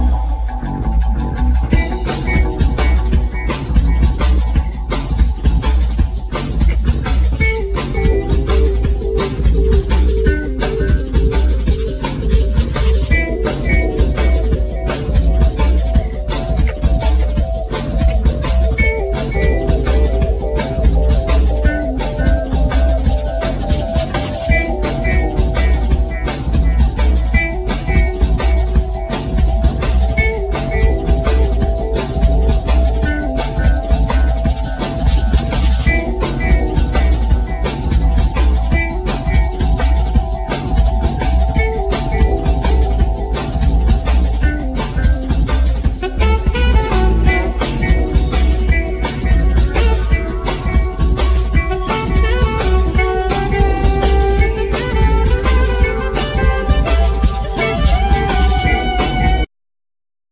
Vocal,Percussions
Alt sax
Drums,Samples
Guitar
Double bass
Trumpet